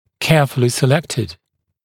[‘keəfulɪ sɪ’lektɪd][‘кеафули си’лэктид]внимательно отобранный, тщательно подобранный